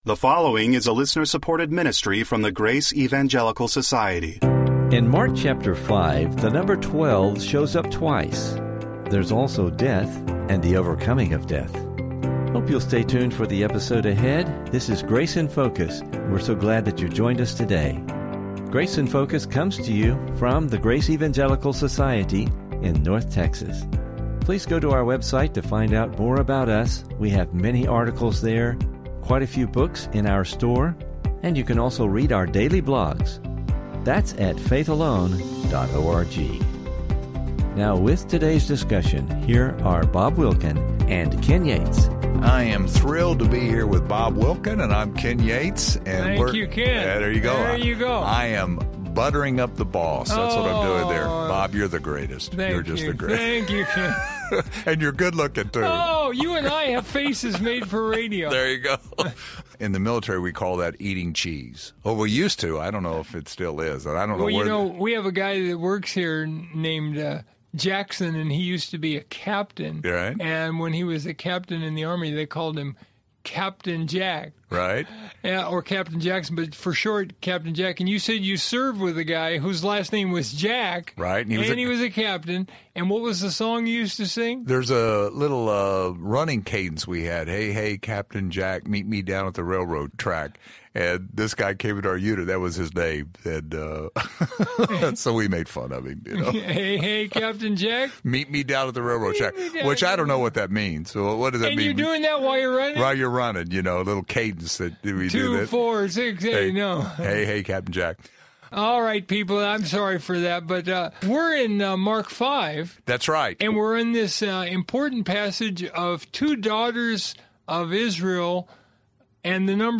There is a dying twelve year-old daughter and on the way to her, Jesus encounters a woman who has a twelve year-old issue of blood, who may have been in a death process as well. Please listen for an interesting discussion and lessons related to these two miracles!